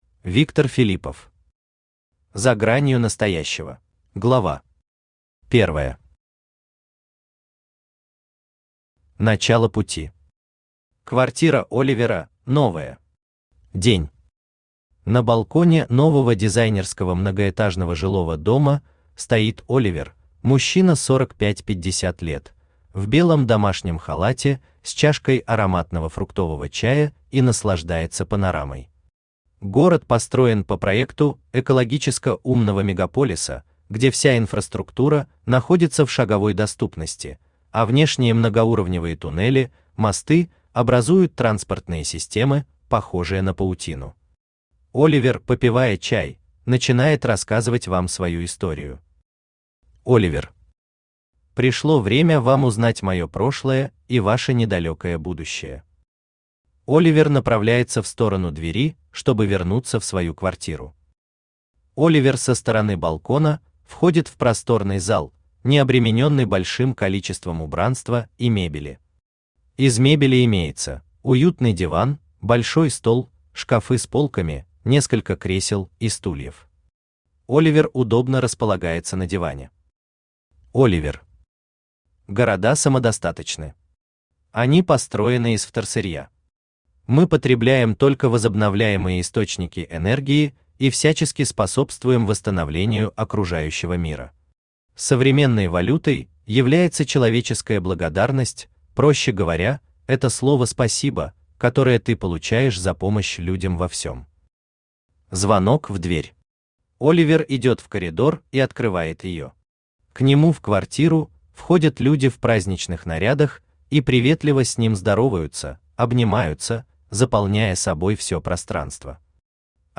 Аудиокнига За гранью настоящего | Библиотека аудиокниг
Aудиокнига За гранью настоящего Автор Виктор Филиппов Читает аудиокнигу Авточтец ЛитРес.